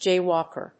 アクセント・音節jáy・wàlk